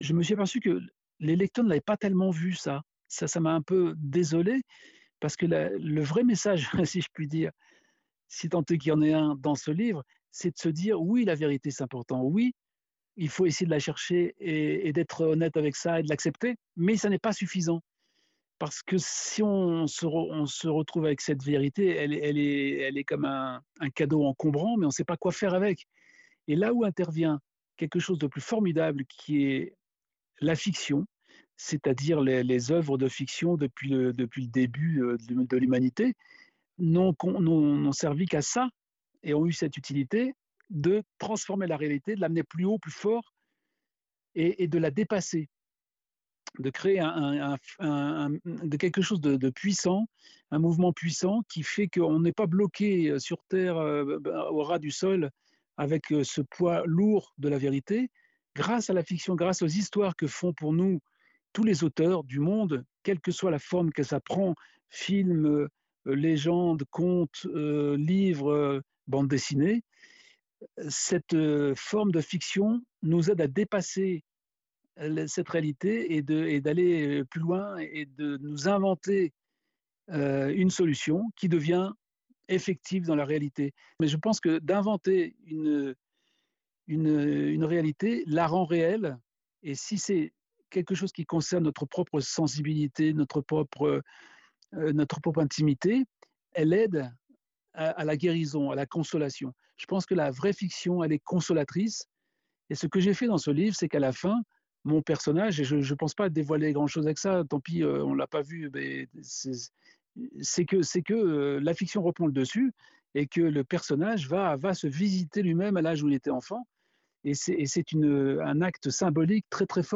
Entretien avec Didier Tronchet